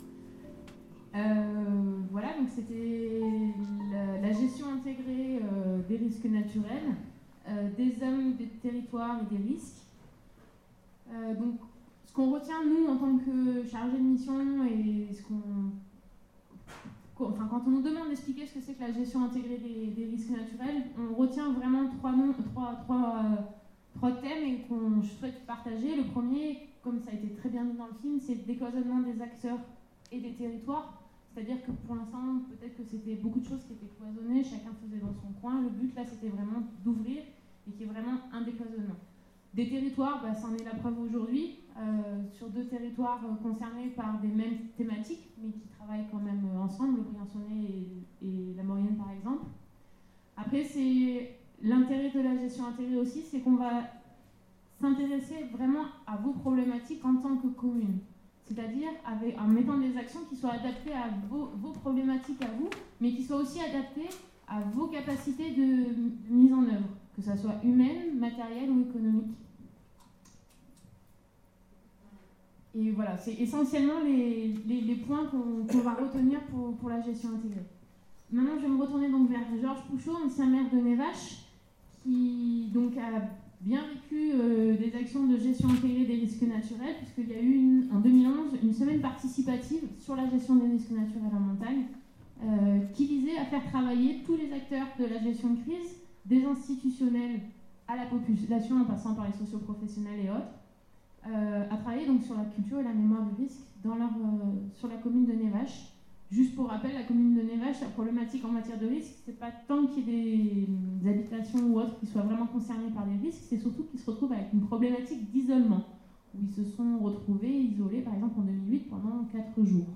Témoignages d’élus impliqués dans la gestion intégrée des risques naturels » Pays de Maurienne - (Site du Syndicat du Pays de Maurienne)
Table_ronde_Risques_partie2.mp3